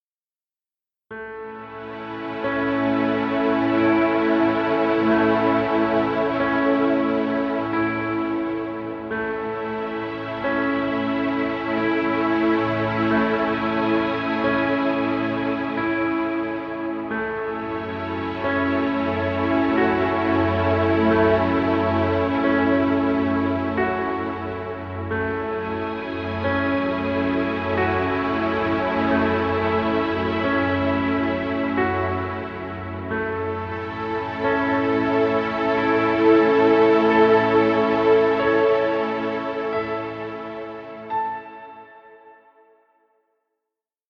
Relaxing piano music. Background music Royalty Free.